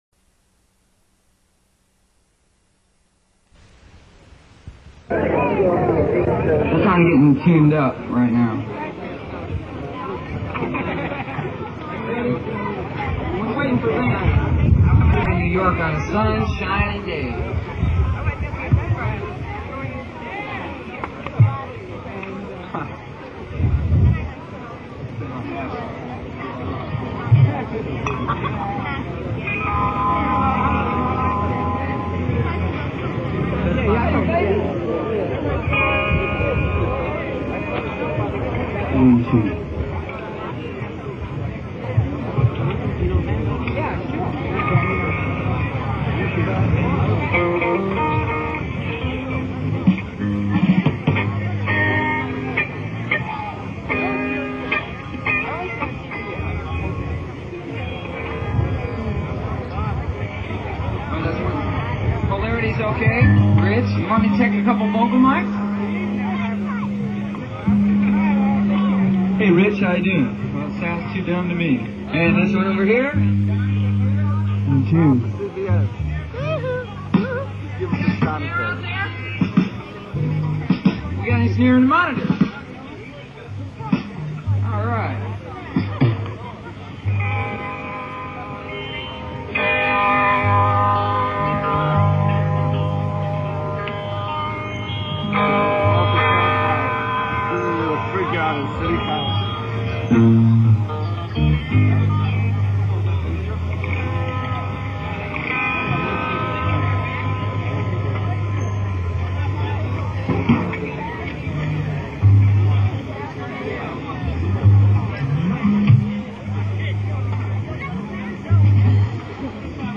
Schaefer Music Festival stage, summer 1973